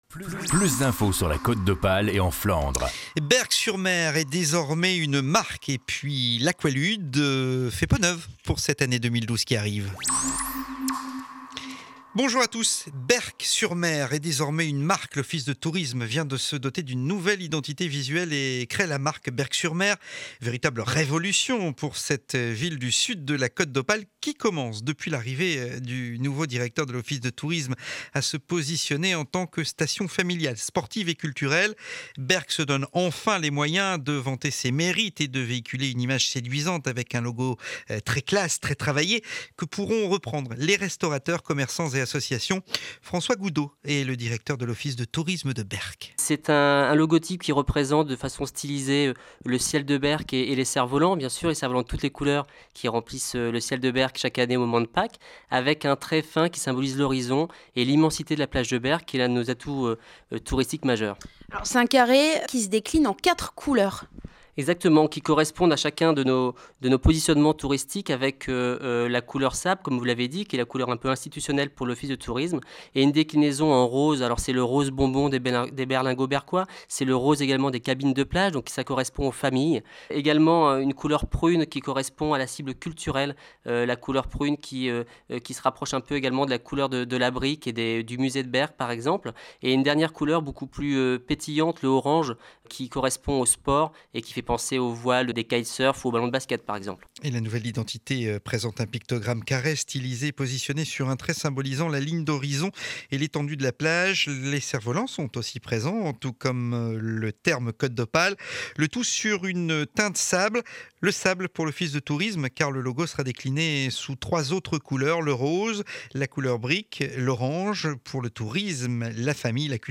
Journal de 12h00 du Jeudi 8 Décembre, édition de Montreuil.